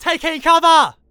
Barklines Combat VA
Added all voice lines in folders into the game folder